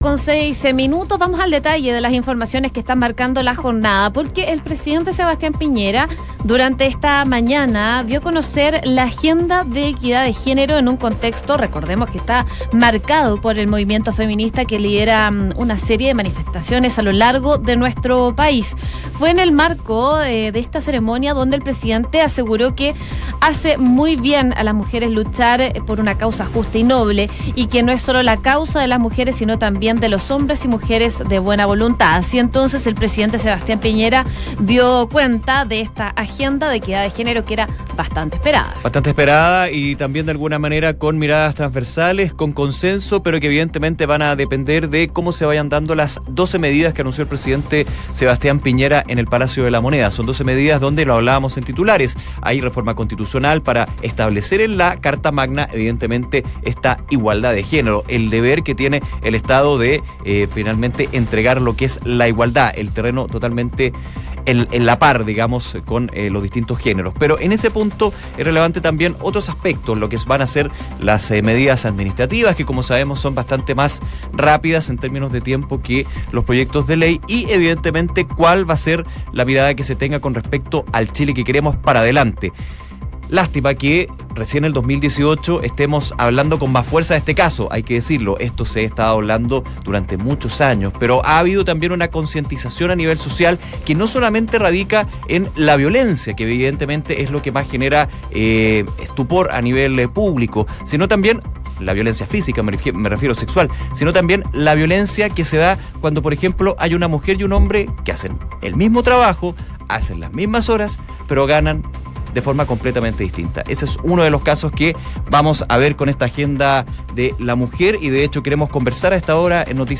La ministra de la Mujer y la Equidad de Género conversó junto a